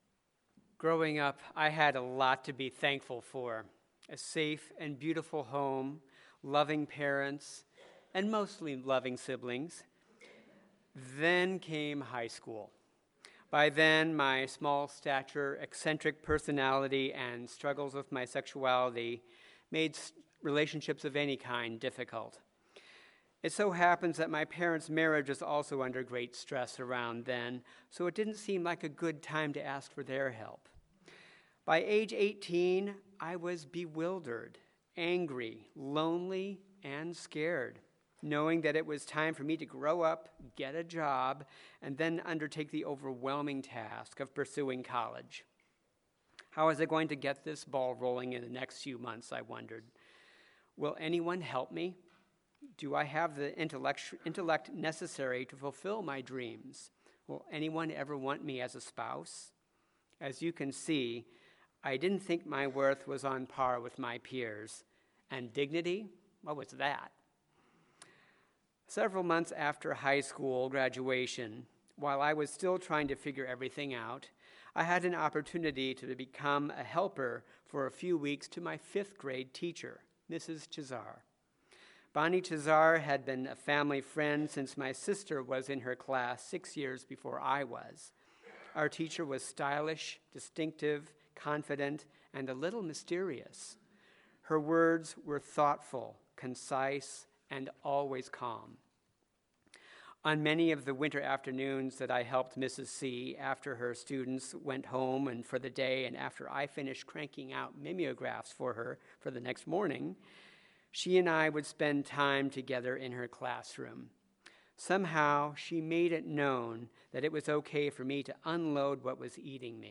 Sermon-3-11-Worth-and-Dignity.mp3